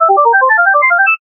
Media:sprout_kill_vo_04.ogg Weird Sound.
奇怪的声音